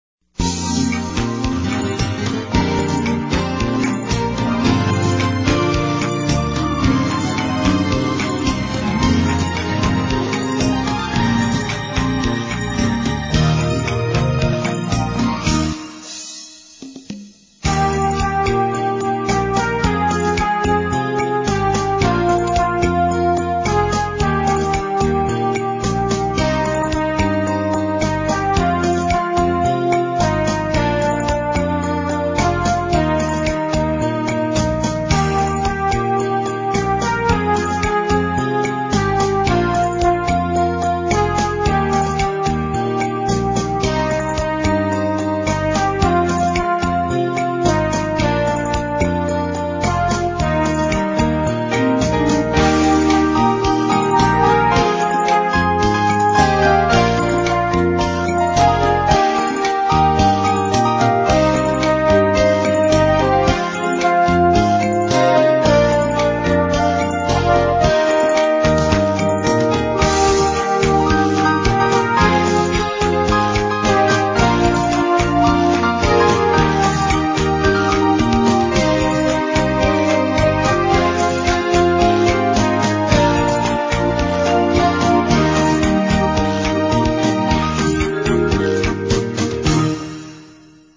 DM Accompaniments